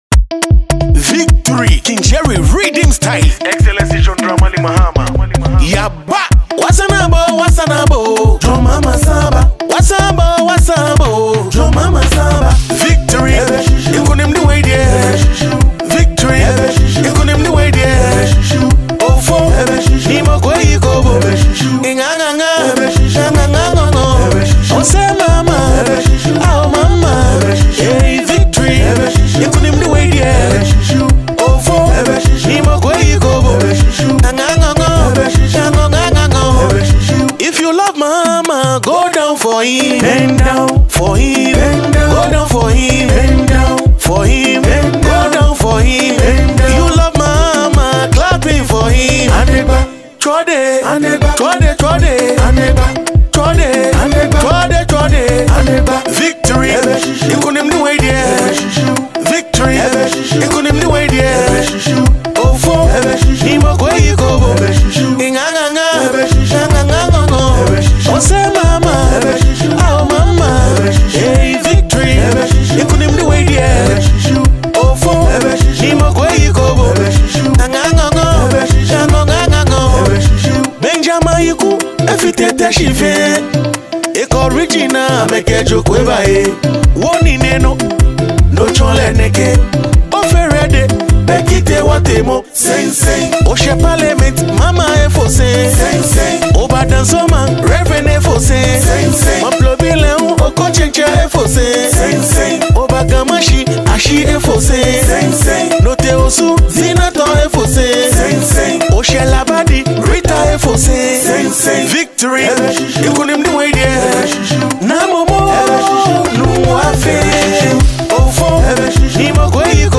a Ghanaian Ga singer